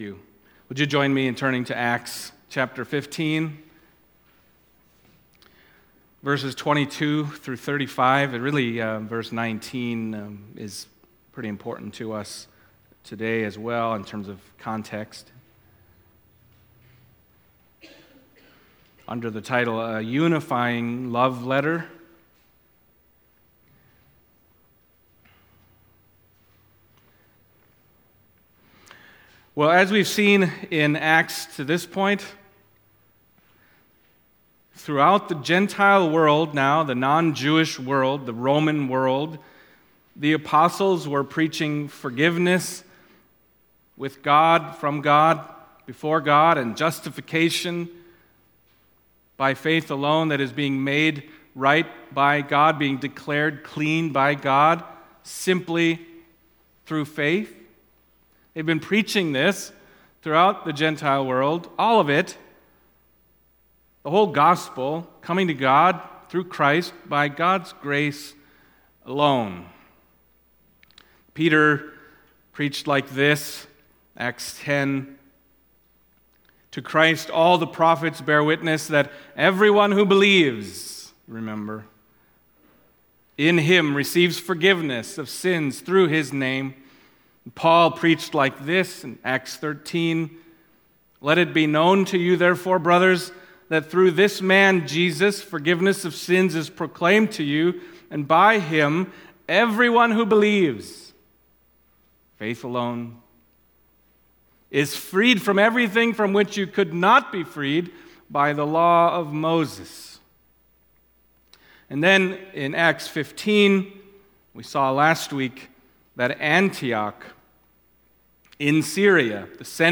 Acts Passage: Acts 15:22-35 Service Type: Sunday Morning Acts 15